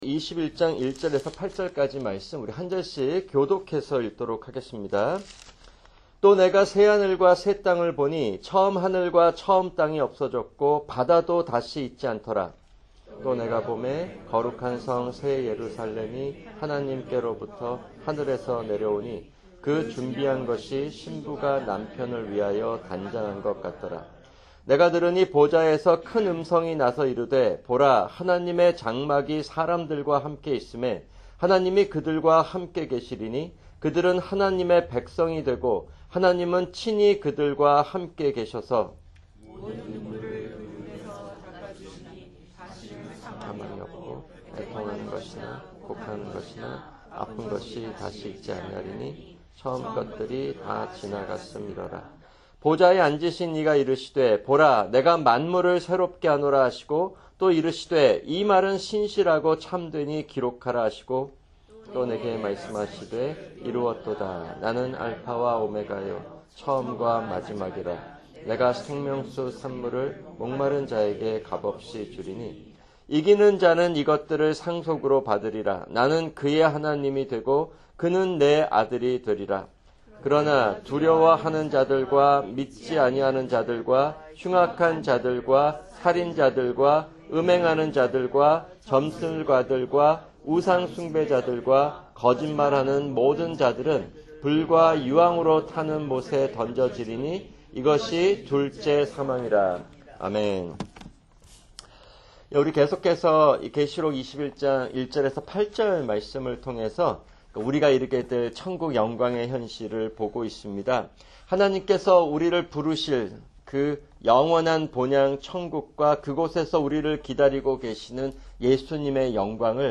[금요 성경공부] 계시록 21:1-8(3)